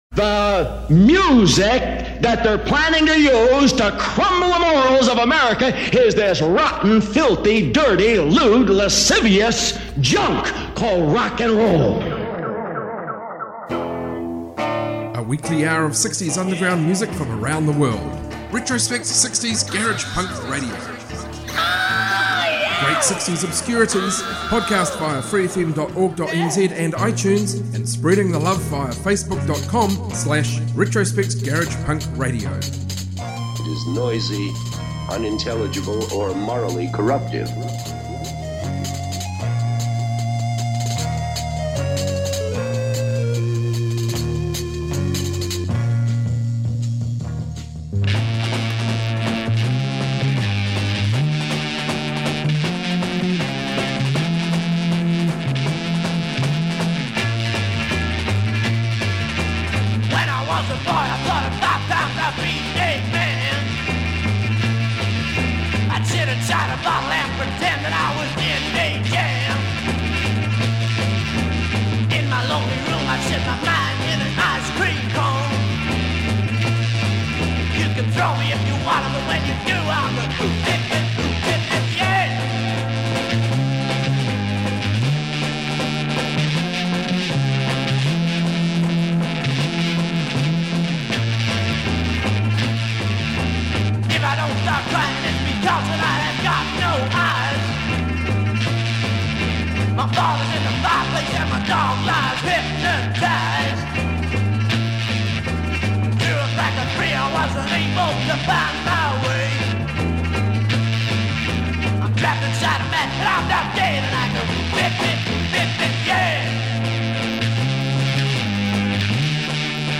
60s Garage Rock, Garage Punk, Proto-Punk, Freakbeat, Psychedelia